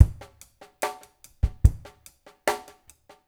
BOL DRUMS 2.wav